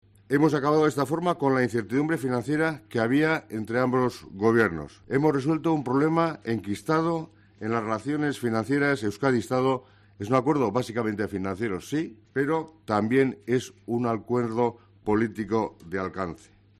El consejero de Hacienda y Economía, Pedro Azpiazu, ha comparecido ante los periodistas en Vitoria para detallar el acuerdo alcanzado entre ambos gobiernos, con el que se pone fin a diez años de encontronazos entre las dos administraciones.